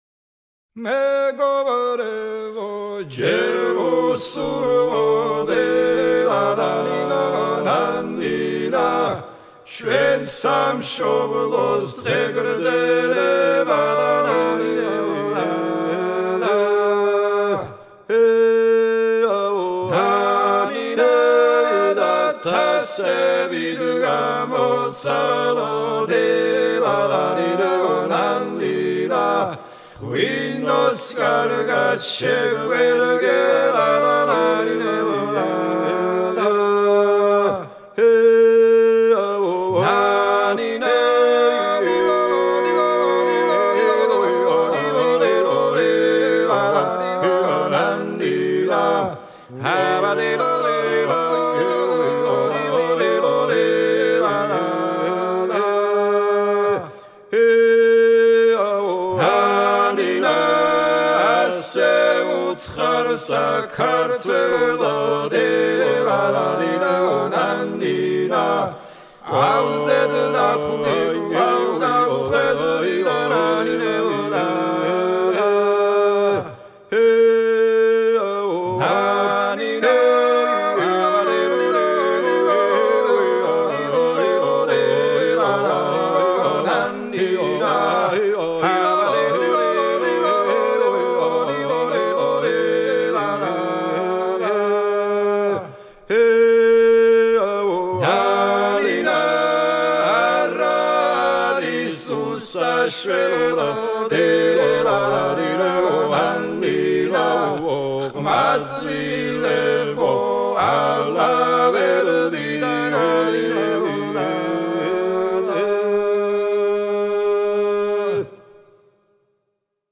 Грустные
Трек размещён в разделе Русские песни / Грузинские песни.